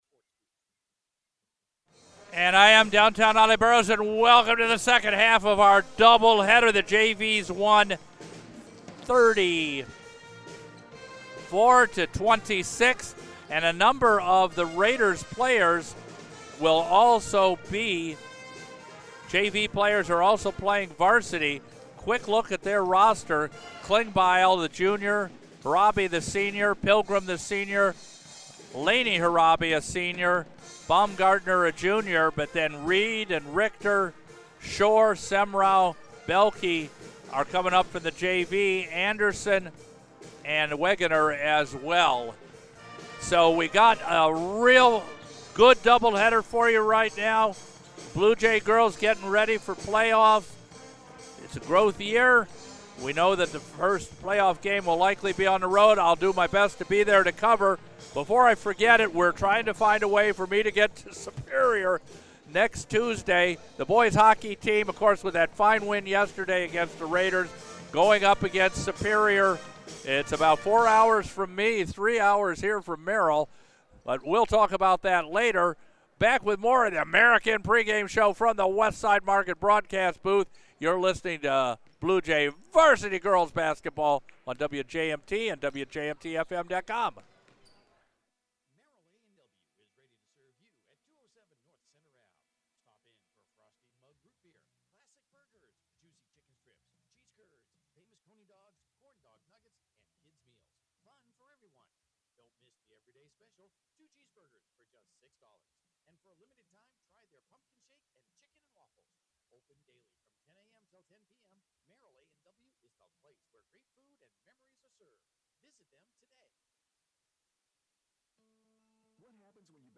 2-10-26 – Medford v Merrill Varsity Girls Basketball